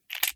38 SPL Revolver - Loading 002.wav